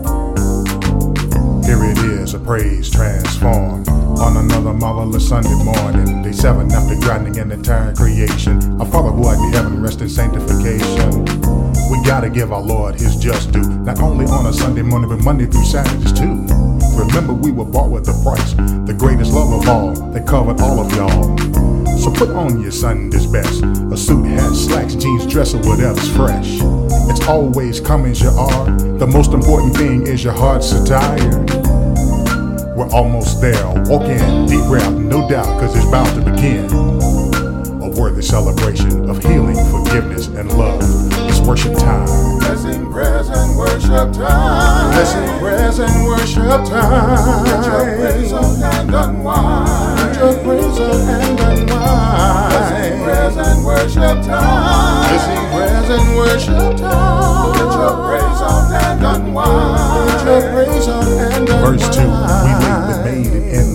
Mastered and produced in Denver Colorado.